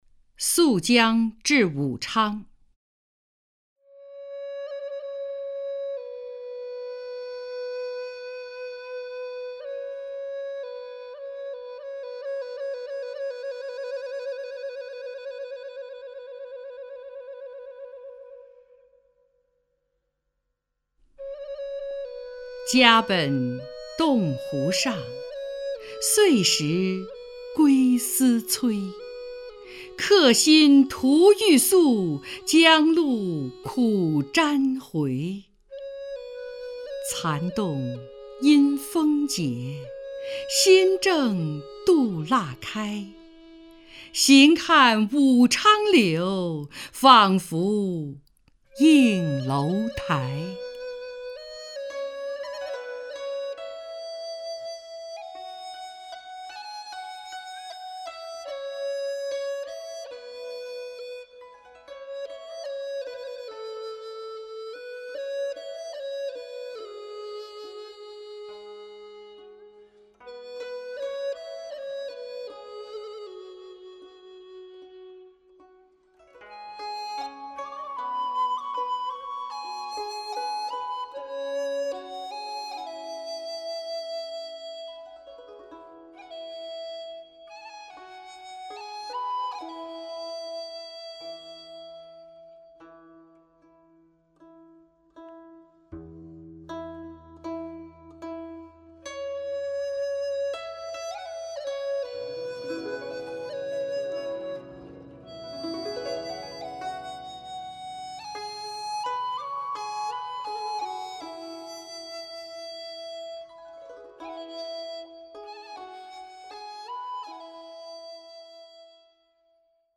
首页 视听 名家朗诵欣赏 张筠英
张筠英朗诵：《溯江至武昌》(（唐）孟浩然)